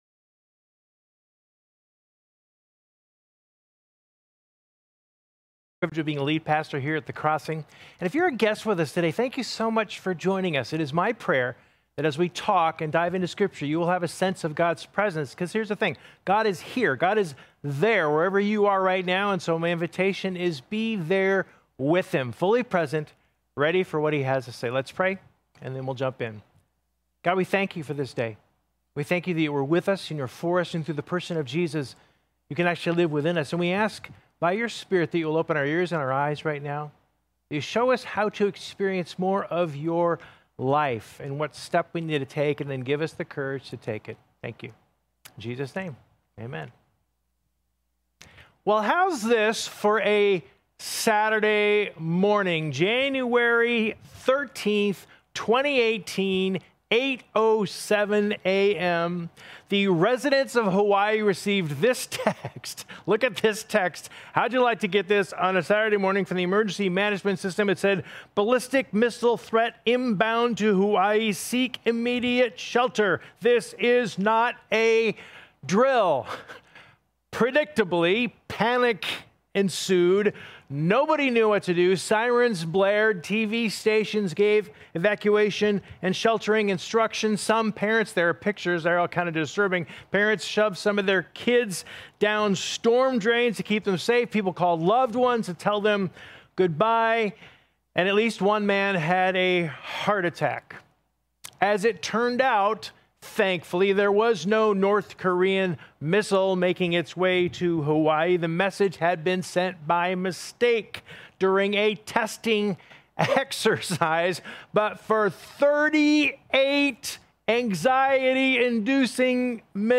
What strategies have you found effective in dealing with jealousy in a godly way? 3. The sermon talked about the fact that we often end up asking God for things that don’t last or aren’t important.